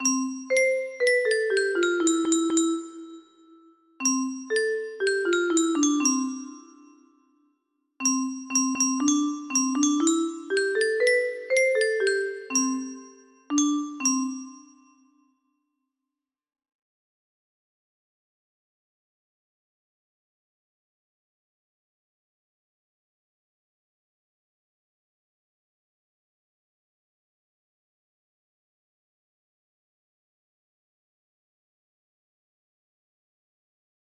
Unknown Artist - Untitled music box melody
Wow! It seems like this melody can be played offline on a 15 note paper strip music box!